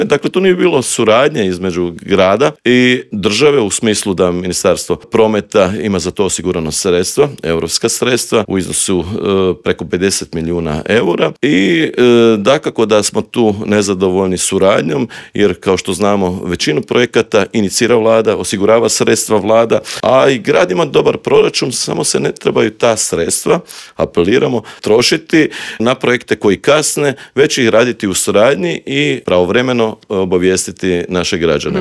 ZAGREB - U novom izdanju Intervjua Media servisa gostovao je predsjednika HDZ-a Novi Zagreb Istok i državnog tajnika u Ministarstvu poljoprivrede Tugomir Majdak s kojim smo prošli teme od gorućih problema u Novom Zagrebu, preko najavljenog prosvjeda u Sisku protiv industrijskih megafarmi i klaonice pilića pa sve do ovisnosti Hrvatske o uvozu hrane.